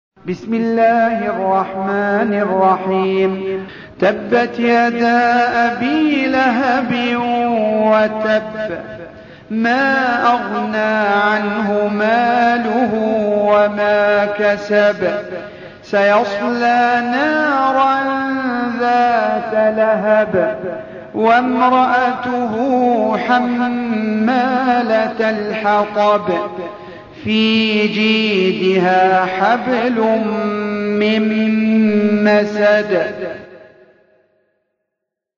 Surah Repeating تكرار السورة Download Surah حمّل السورة Reciting Murattalah Audio for 111. Surah Al-Masad سورة المسد N.B *Surah Includes Al-Basmalah Reciters Sequents تتابع التلاوات Reciters Repeats تكرار التلاوات